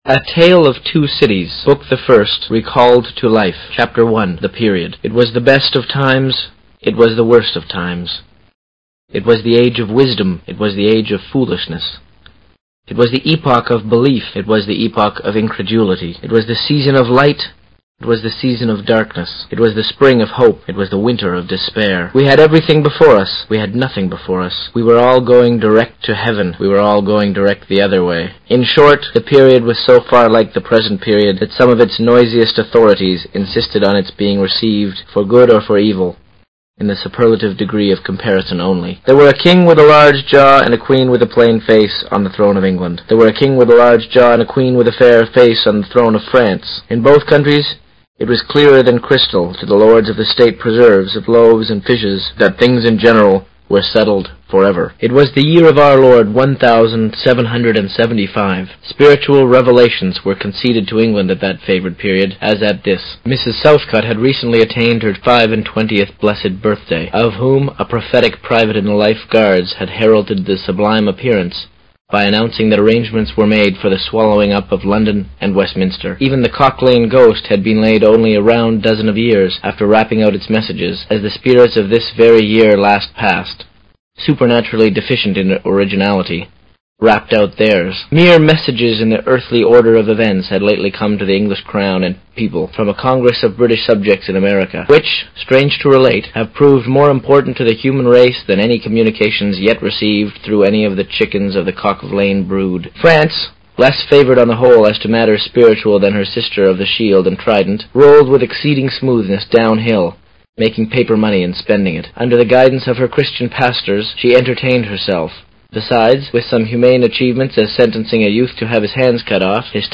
有声名著之双城记Book1 Chapter01 听力文件下载—在线英语听力室